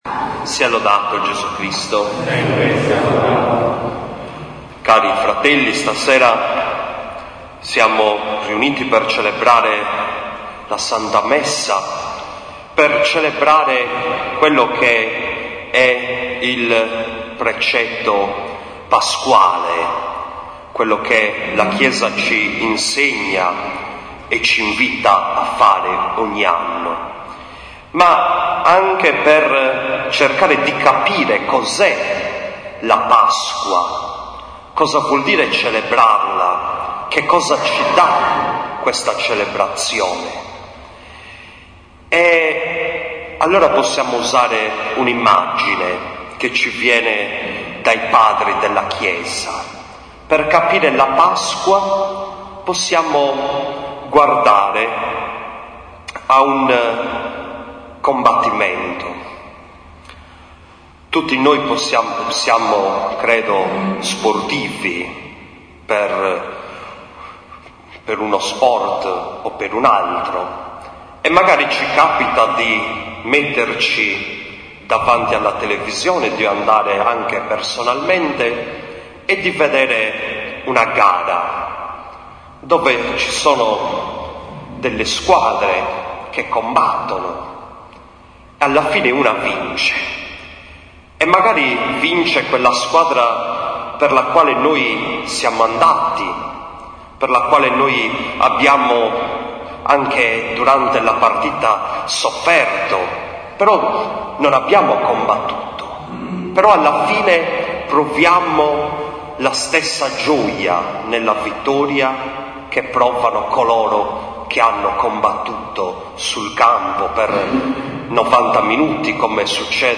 7.04.2014 – OMELIA DEL PRECETTO PASQUALE DEGLI UOMINI IN OCCASIONE DELLE SS. QUARANTORE